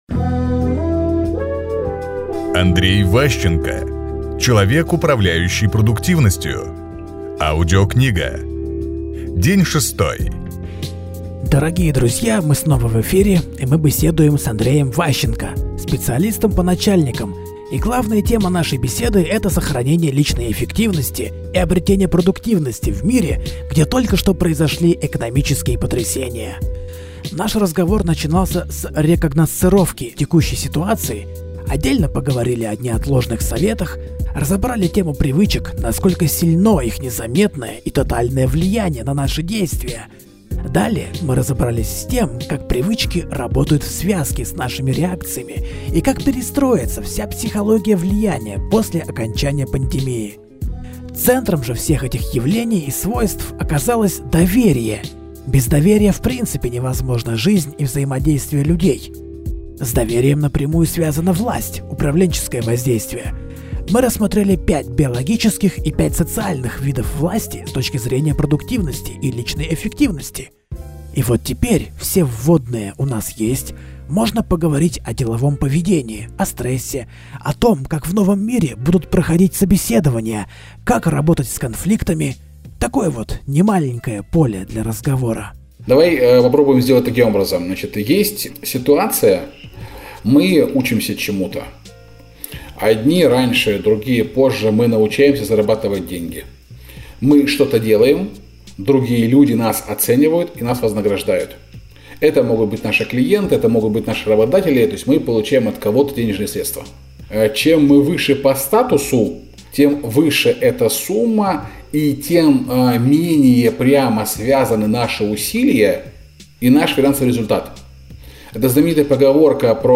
Аудиокнига Человек, управляющий продуктивностью. Часть 6 | Библиотека аудиокниг